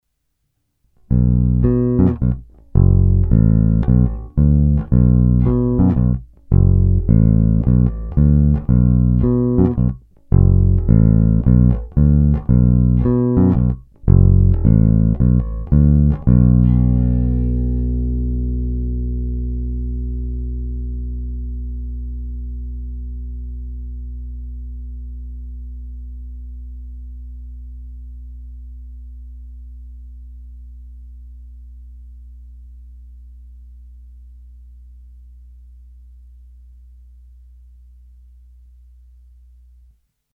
Bručivá, agresívnější, skvěle použitelná i na slapovou techniku.
Není-li uvedeno jinak, všechny nahrávky byly provedeny rovnou do zvukové karty a dále kromě normalizace ponechány bez úprav. Tónová clona byla vždy plně otevřená.
Hra nad snímačem